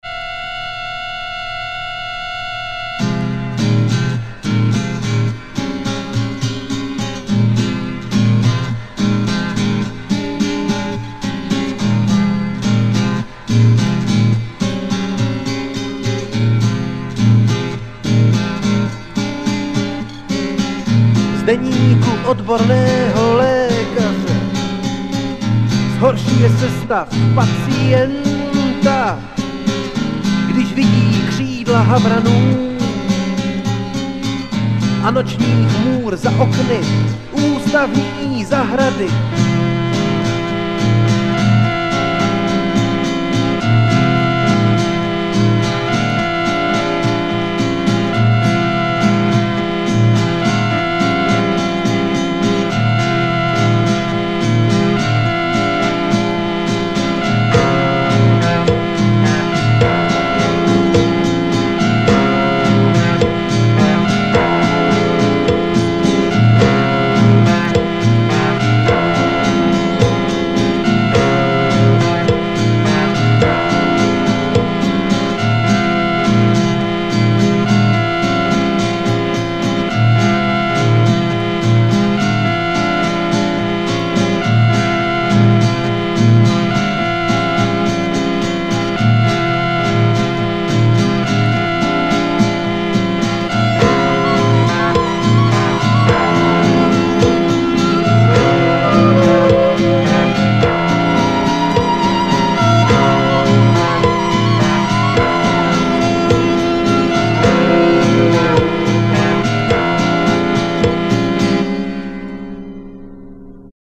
art-rock/mininal/alternatíva z Valašska.